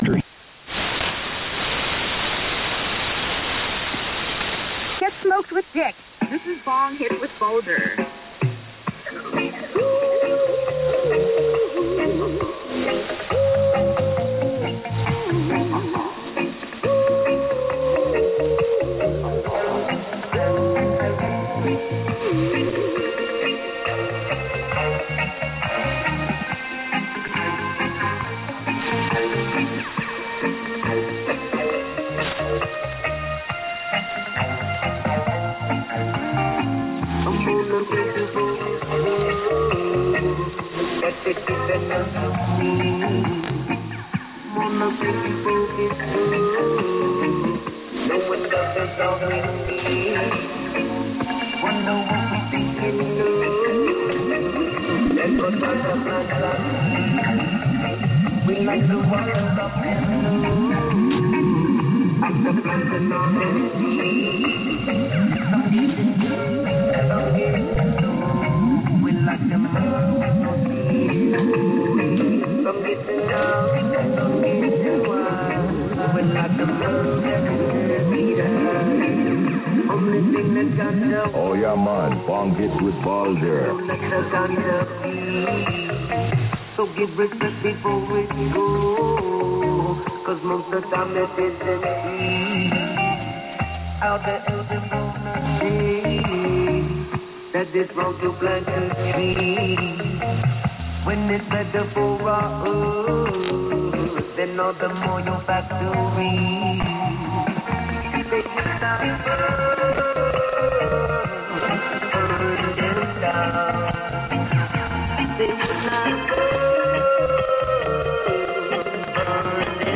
SDR recording catch, 2325 sign on, excellent signal quality here.